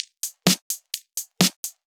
Index of /VEE/VEE Electro Loops 128 BPM
VEE Electro Loop 338.wav